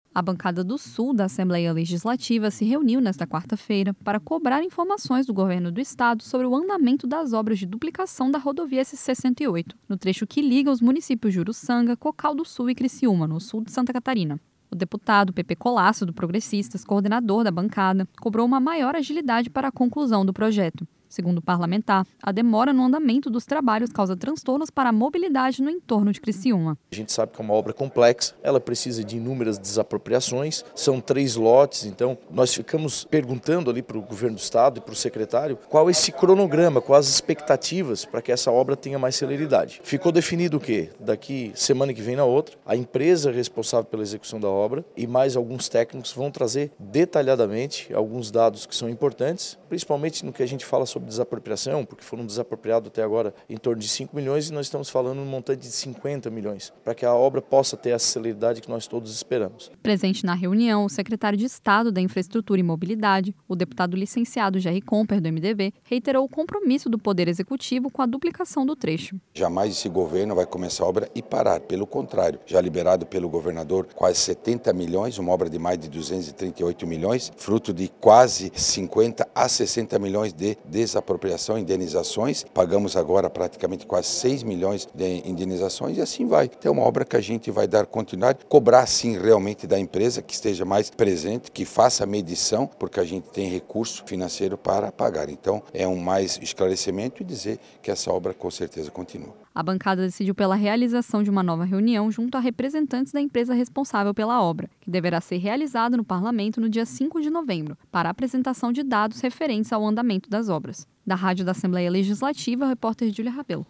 Entrevistas com:
- deputado licenciado Jerry Comper (MDB), secretário de Estado da Infraestrutura e Mobilidade;
- deputado Pepê Collaço (Progressistas), coordenador da Bancada do Sul.